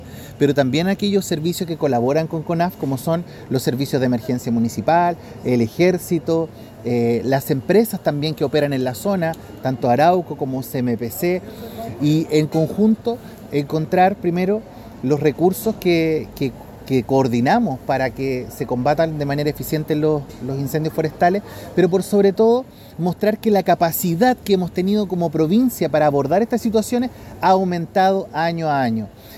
Al respecto, el delegado Presidencial Provincial de Bío Bío, Javier Fuchslocher, señaló que esperan disminuir la cantidad de siniestros esta temporada.